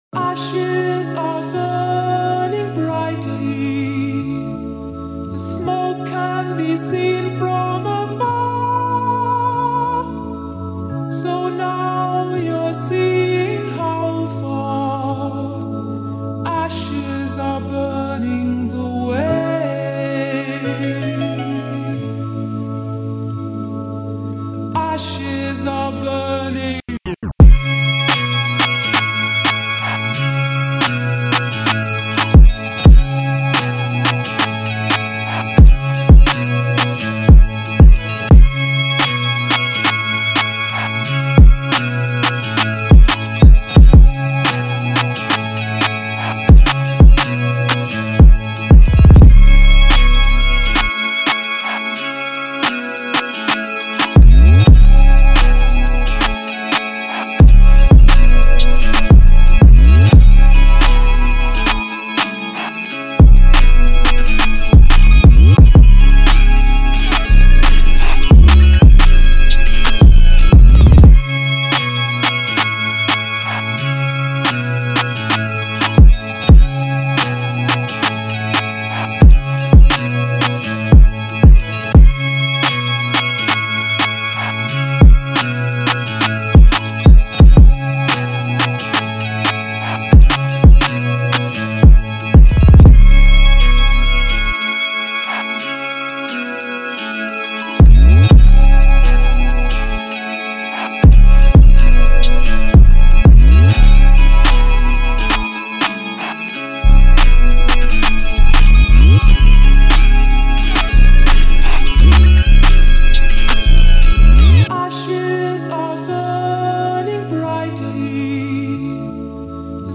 Official Instrumentals , Rap Instrumentals